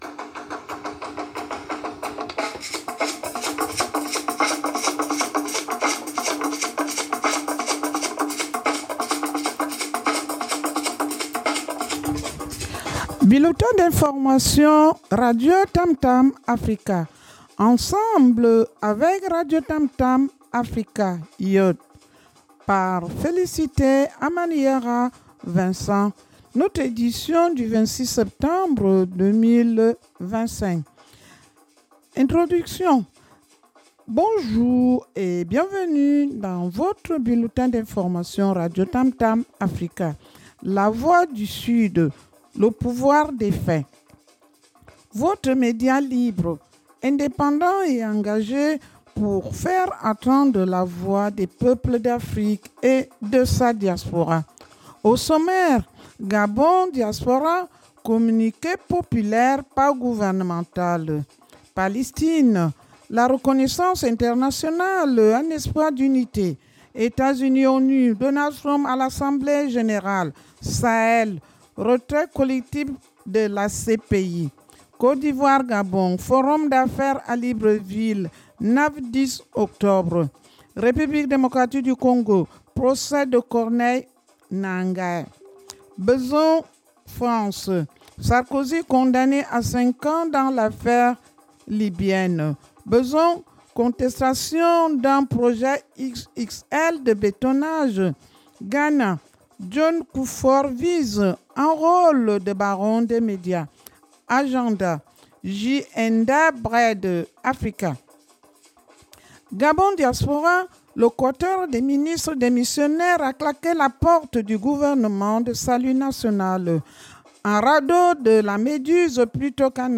Radio TAMTAM AFRICA BULLETIN D’INFORMATION – RADIOTAMTAM AFRICA BULLETIN D’INFORMATION 26 septembre 2025